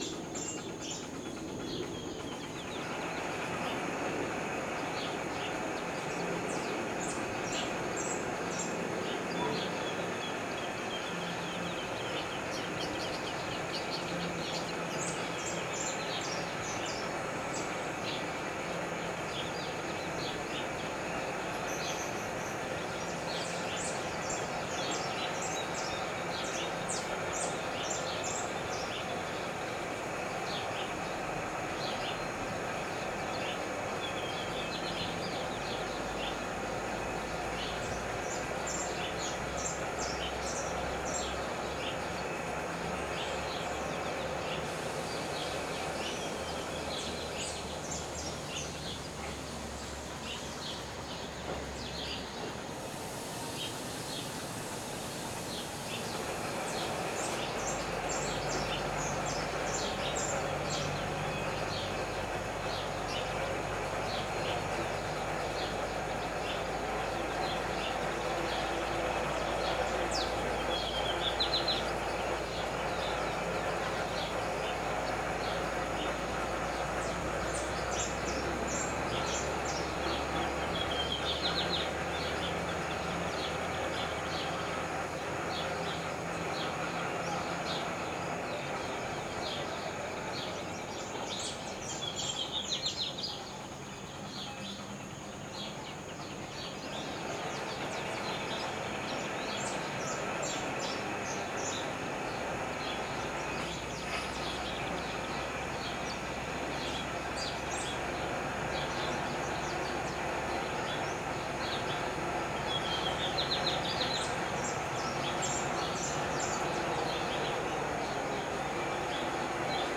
Chaco, Barranqueras, Río Paraná
Camping Municipal 15 hs. 15 de Noviembre 2023
esa-chaco-barranqueras-rio-parana.mp3